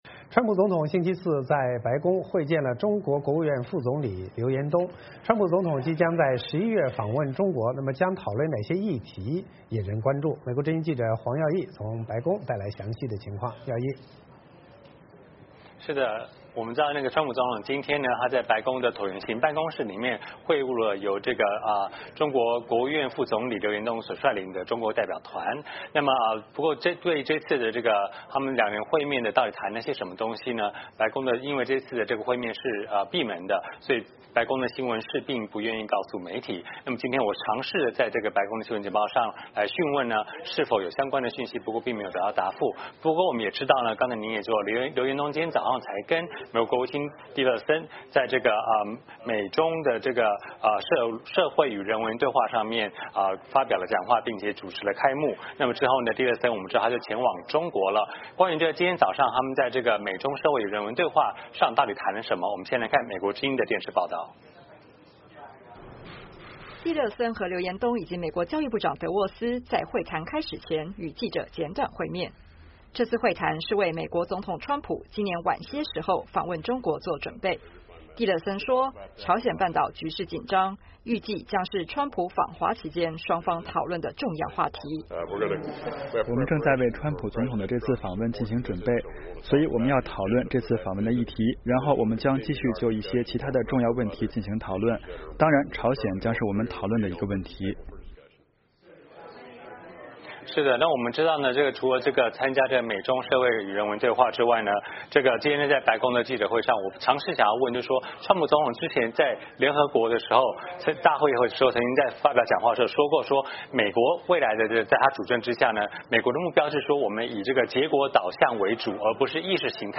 VOA连线：川普在白宫会见中国副总理刘延东